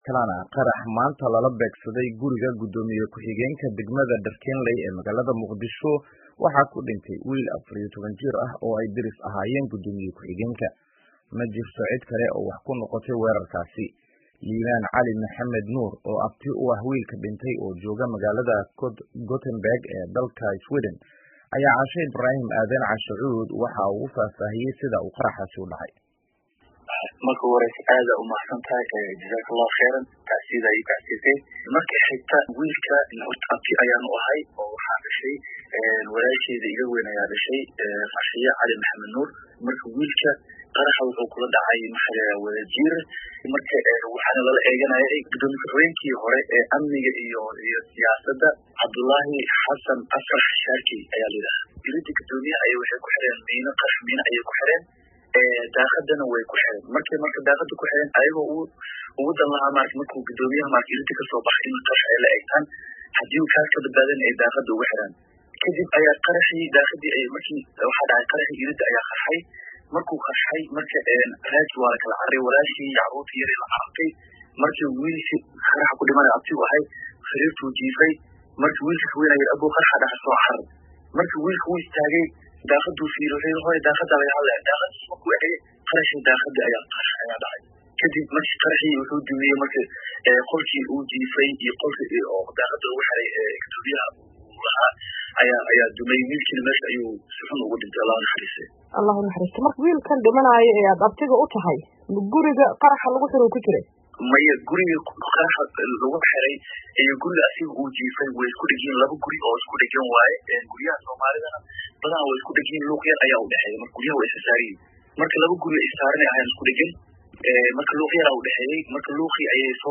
Wareysi: Qarax dhimasho sababay oo ka dhacay Muqdisho